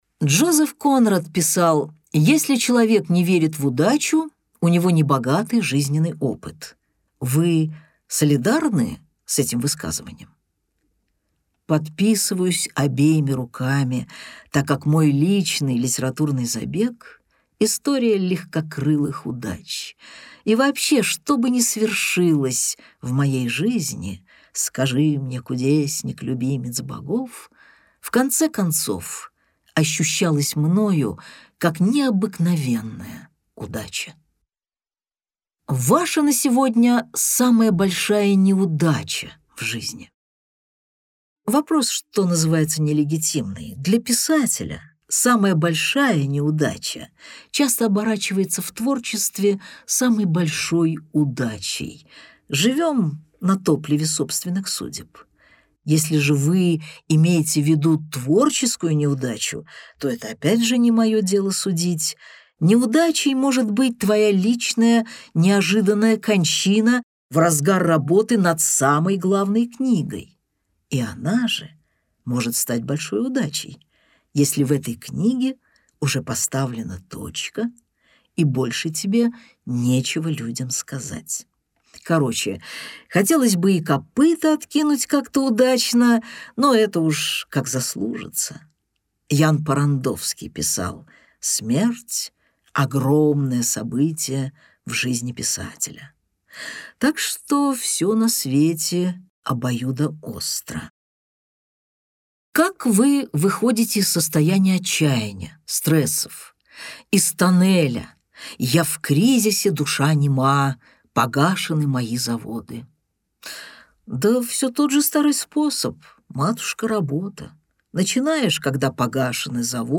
Аудиокнига Больно только когда смеюсь | Библиотека аудиокниг
Aудиокнига Больно только когда смеюсь Автор Дина Рубина Читает аудиокнигу Дина Рубина.